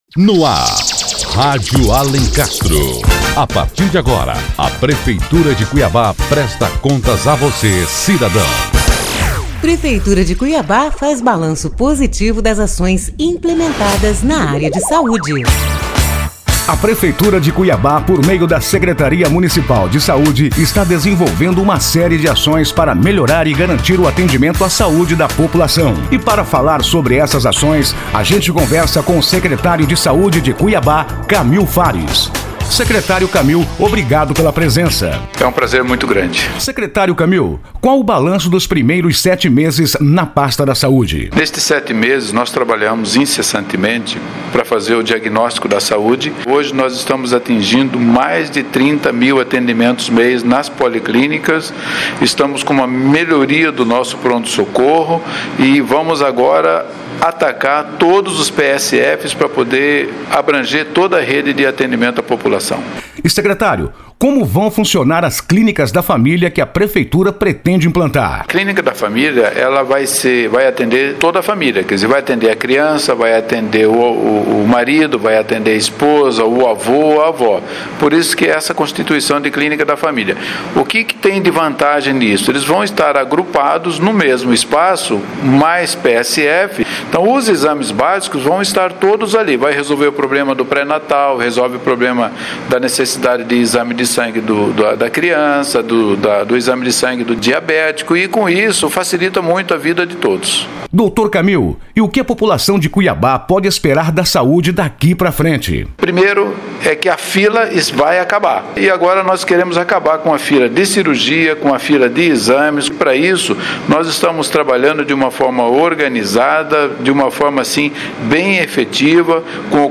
Ouça a entrevista feita com o ex-Secretário de Saúde Kamil Fares para saber mais sobre as ações implementadas...